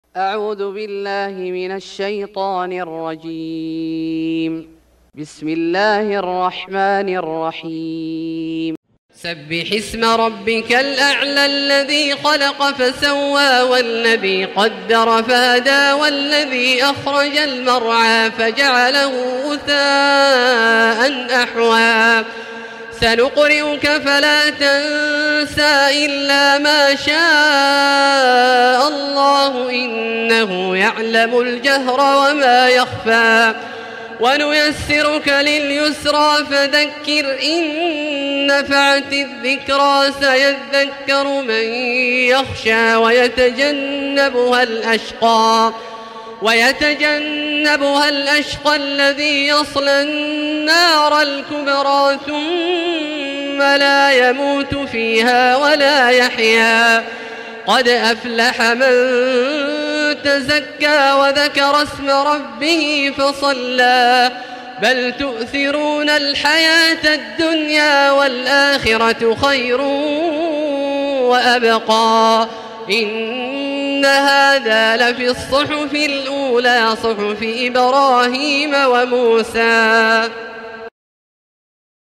سورة الأعلى Surat Al-Ala > مصحف الشيخ عبدالله الجهني من الحرم المكي > المصحف - تلاوات الحرمين